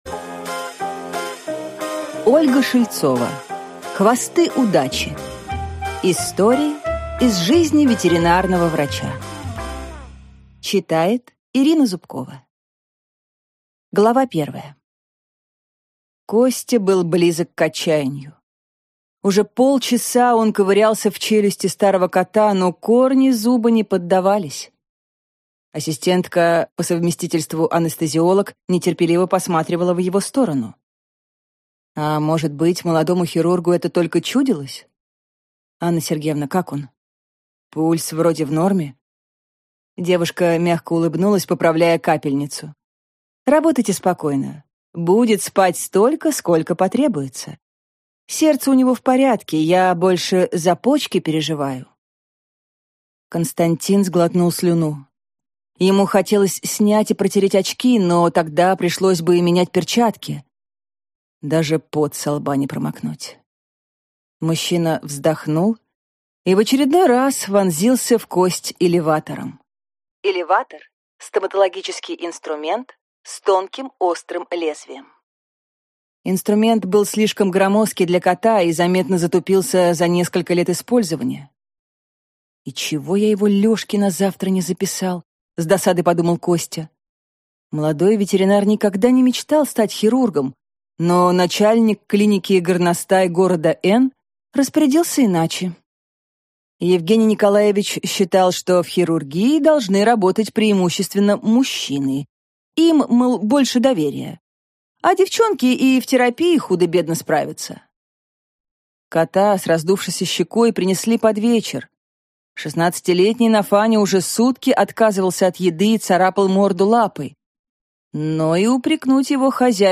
Аудиокнига Хвосты удачи. Истории из жизни ветеринарного врача | Библиотека аудиокниг